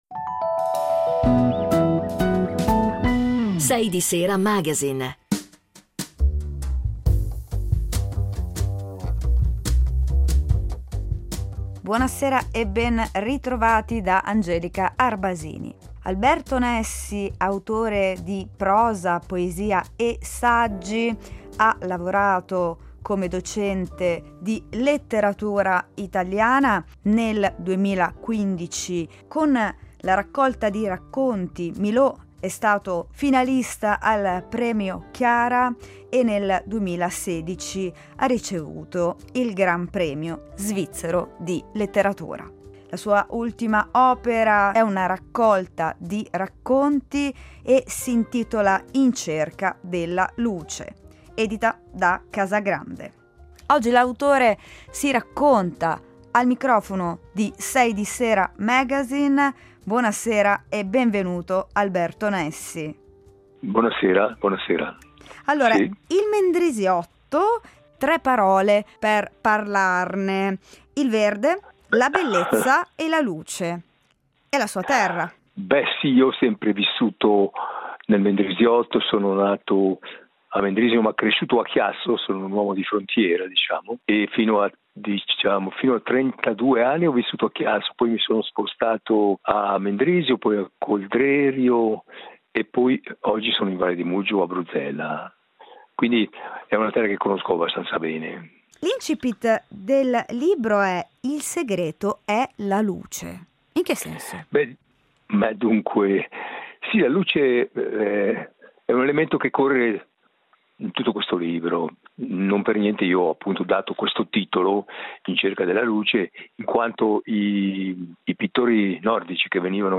Incontro con il poeta Alberto Nessi